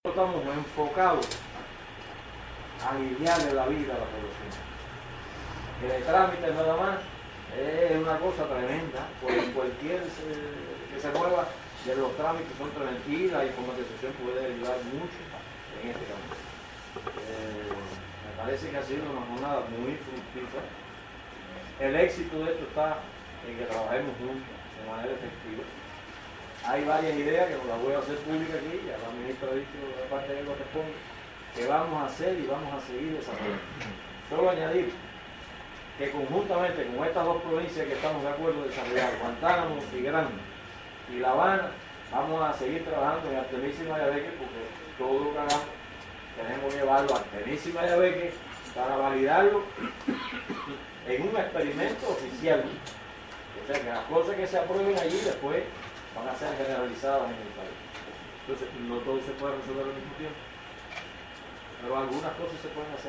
Palabras-de-Maimir-Mesa-Ramos.mp3